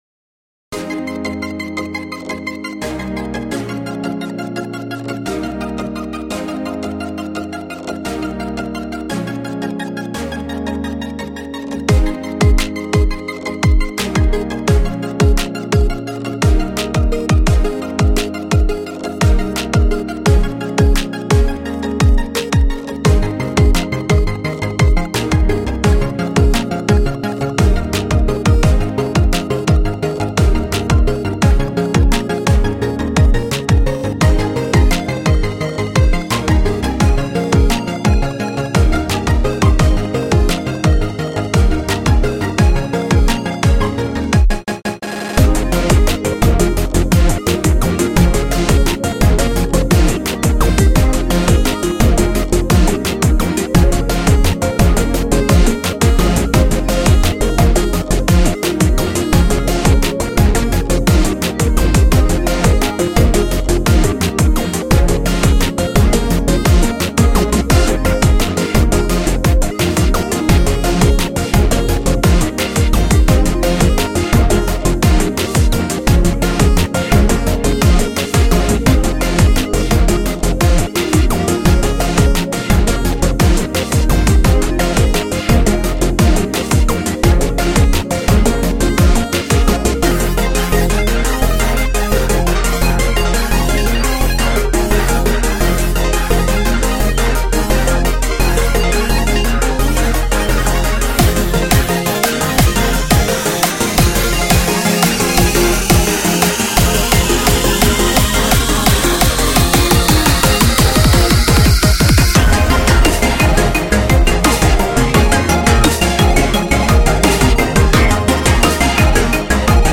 Song off vocal　 BPM:170　 EDM
オススメ エモ オフボ ムーディー